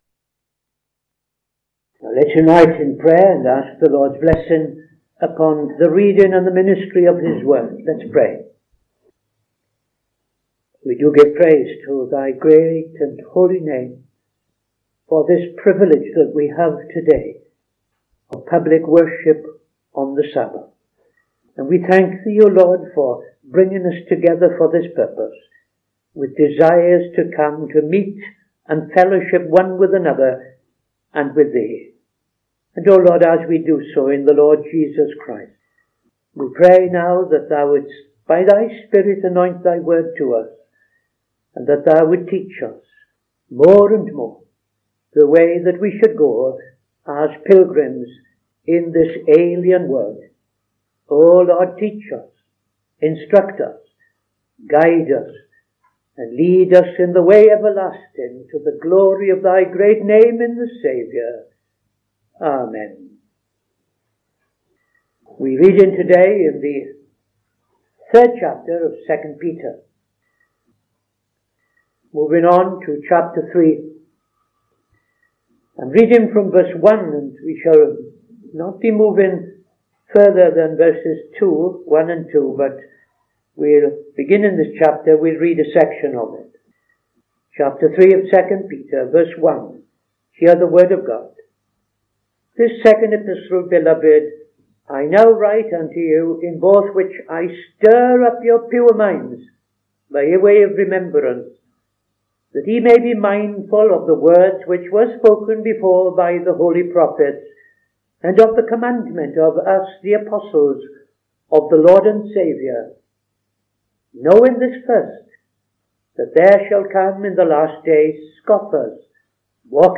Saturday Sermon - TFCChurch
Opening Prayer and Reading II Peter 3:1-13